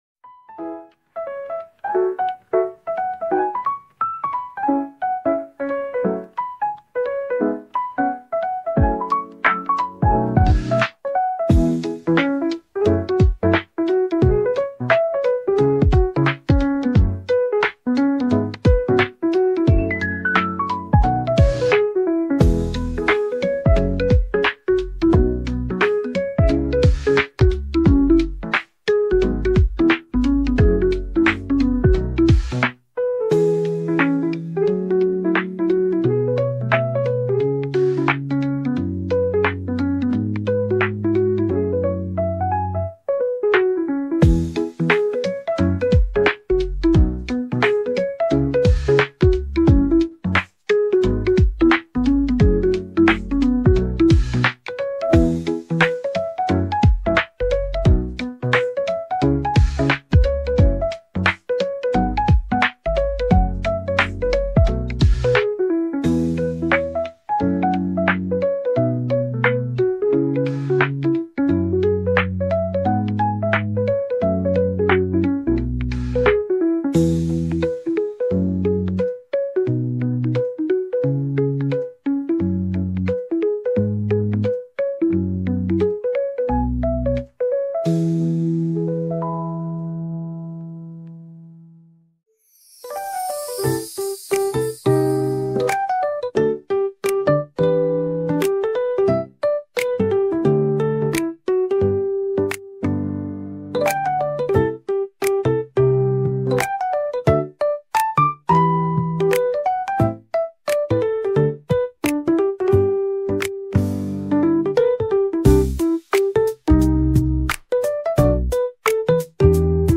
your ultimate destination for calming vibes, chill beats